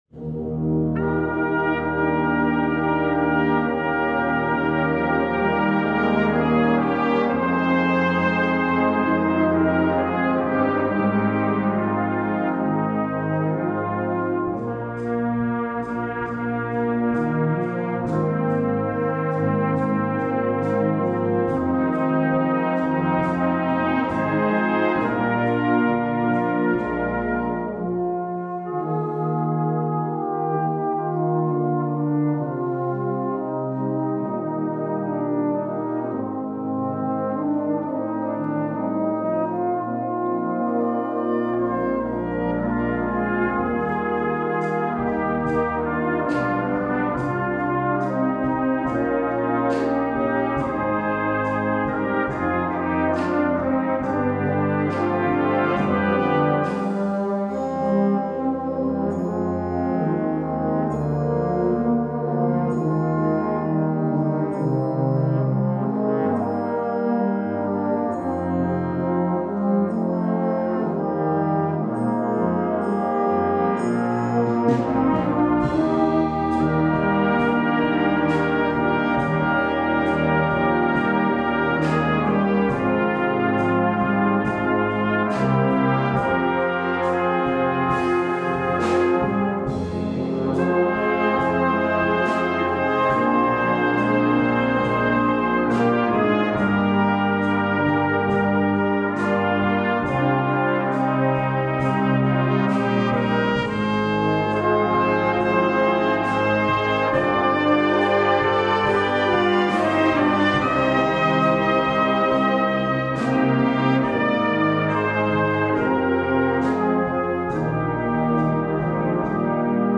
(Song Arrangement)